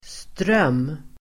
Uttal: [ström:]